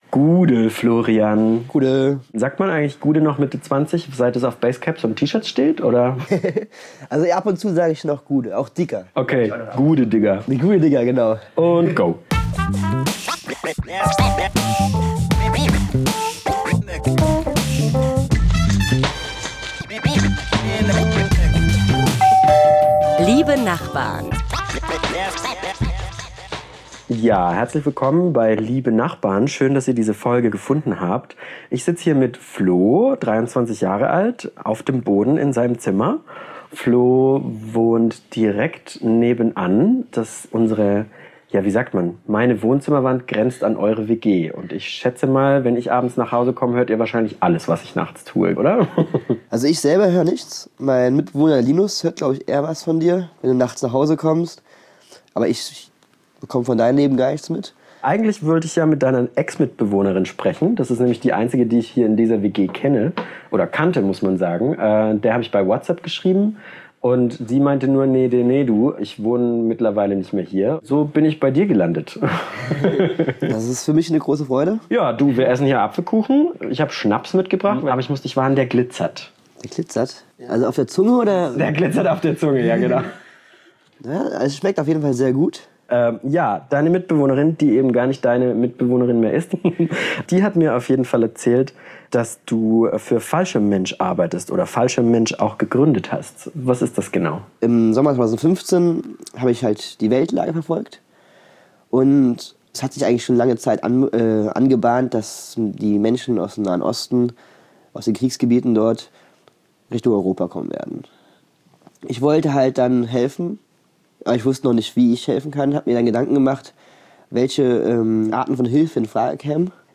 Jeden Dienstag klingel ich an einer fremden Tür in Mainz und quatsche mit jede*m, die mir aufmacht.